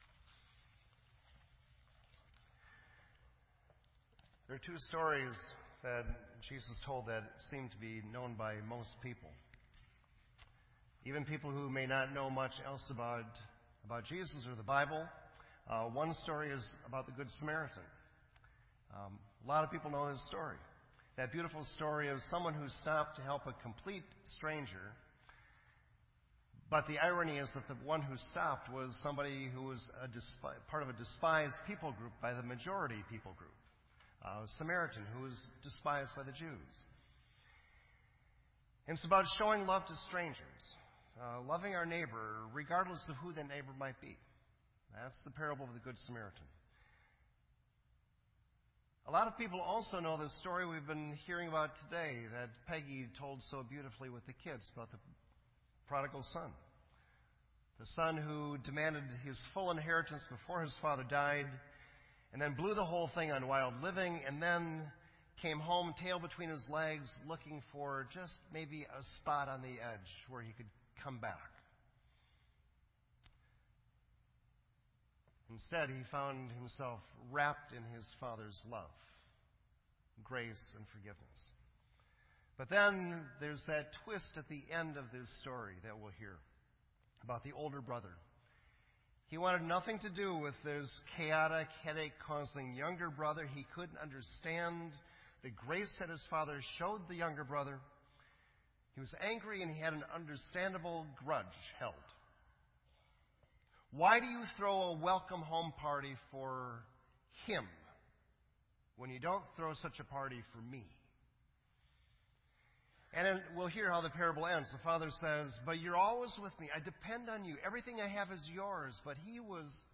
This entry was posted in Sermon Audio on April 1